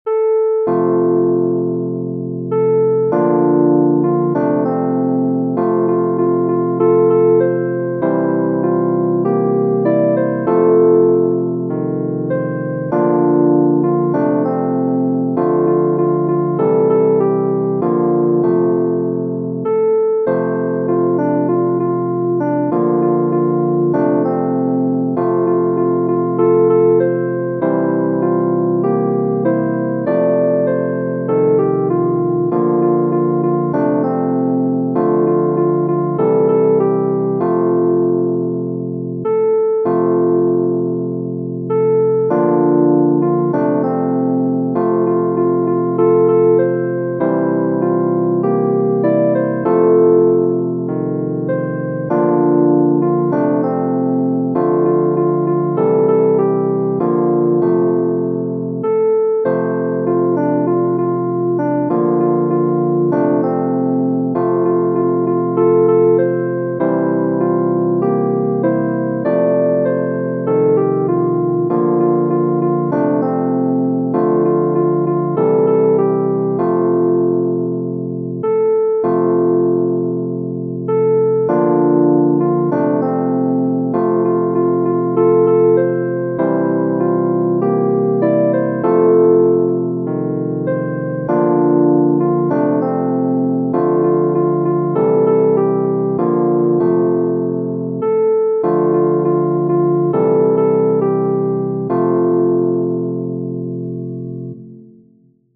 Willis, W. Genere: Religiose Text by Wallace Willis Refrain: Swing low, sweet chariot,[note 1] Coming for to carry me home.